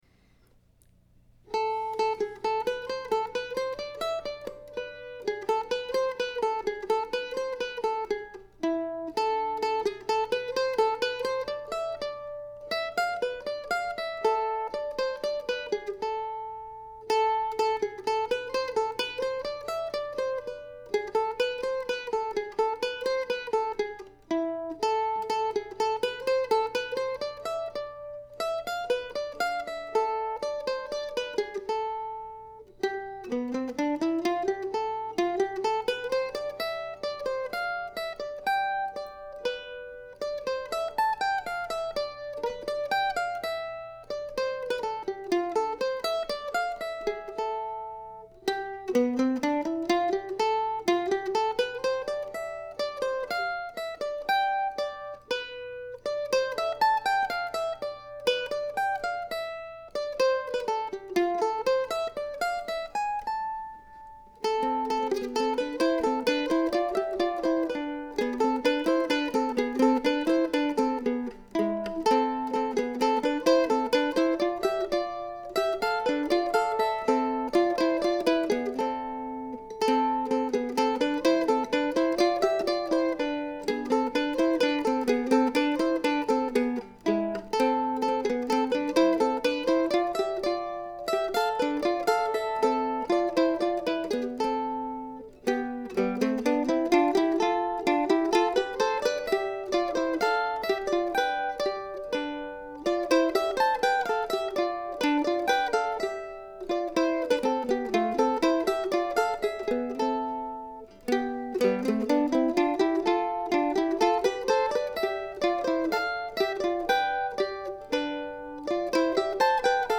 Plus I had an equipment failure in my little toy studio and things take longer to get through the post these days.
In any event here's what is intended to be a pleasant piece titled after some recent birthdays.